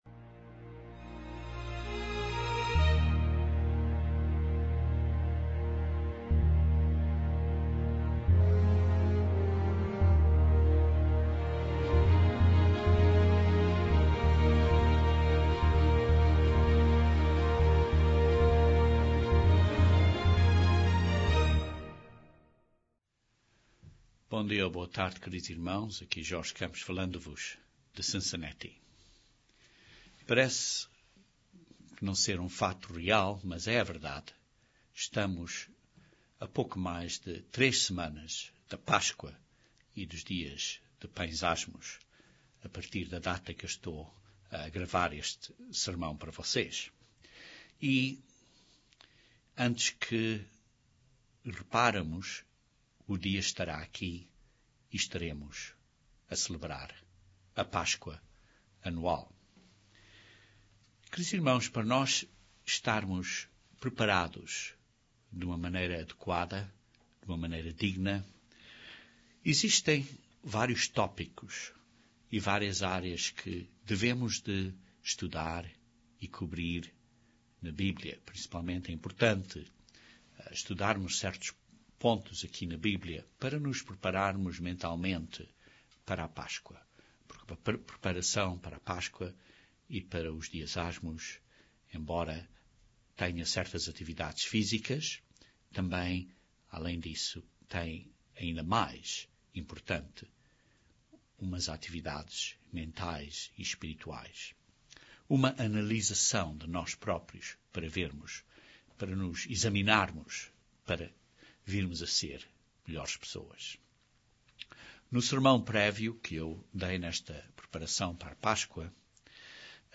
Este sermão descreve alguns pontos importantes da nossa auto-examinação para a Páscoa.